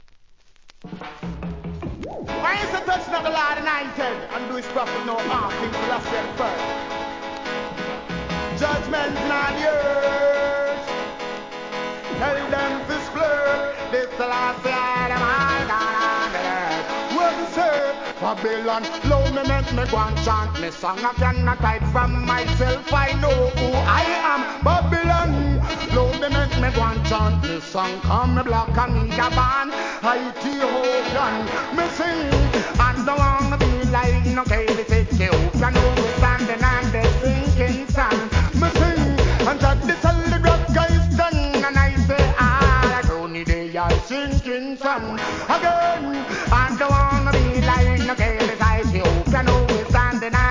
A-B (序盤で数周ノイズ)
REGGAE